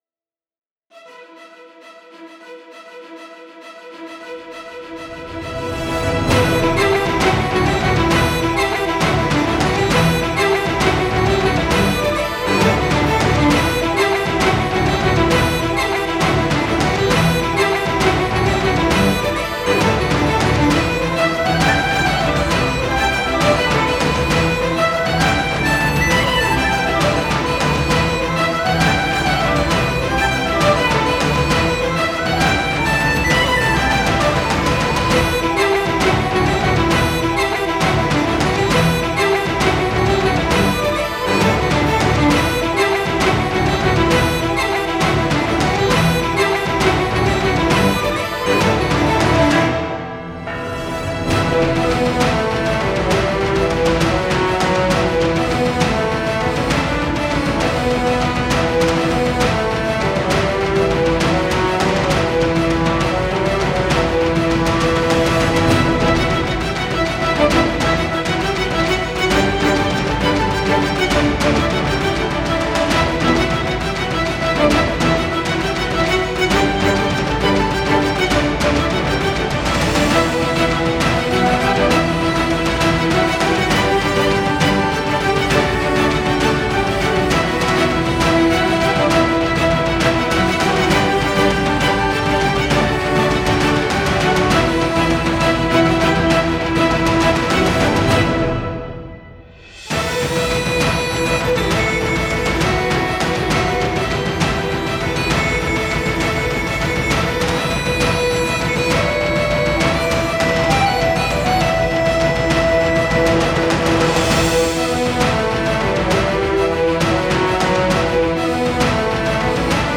Кельтская